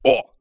ZS受伤1.wav
ZS受伤1.wav 0:00.00 0:00.41 ZS受伤1.wav WAV · 36 KB · 單聲道 (1ch) 下载文件 本站所有音效均采用 CC0 授权 ，可免费用于商业与个人项目，无需署名。
人声采集素材/男3战士型/ZS受伤1.wav